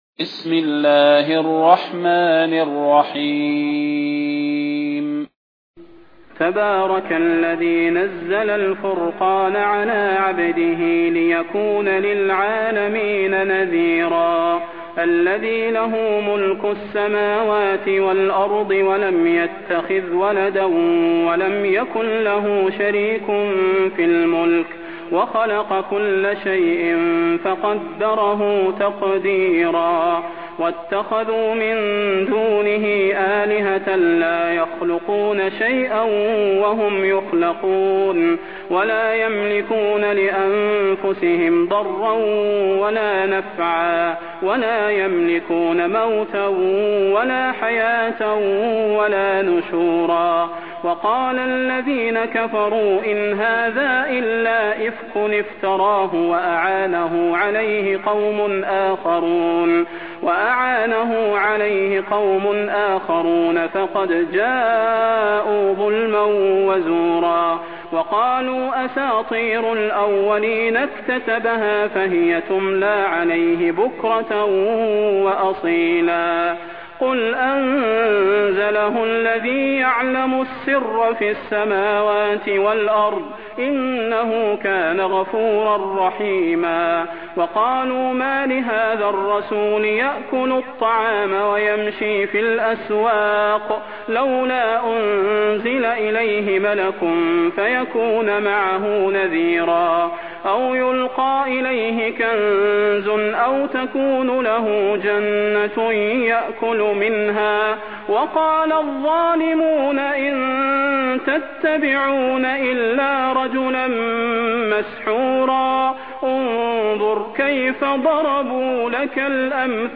المكان: المسجد النبوي الشيخ: فضيلة الشيخ د. صلاح بن محمد البدير فضيلة الشيخ د. صلاح بن محمد البدير الفرقان